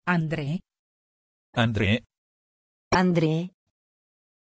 Pronunciación de Andrée
pronunciation-andrée-it.mp3